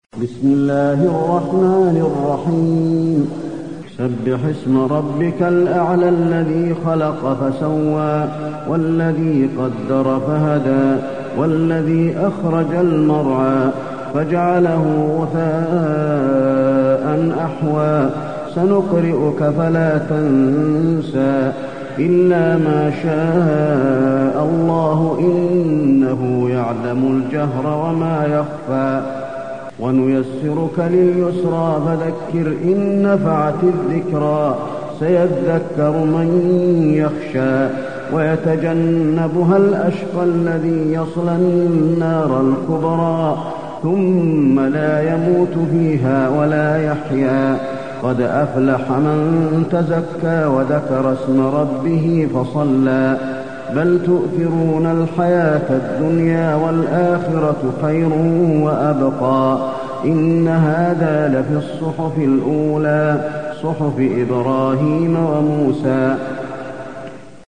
المكان: المسجد النبوي الأعلى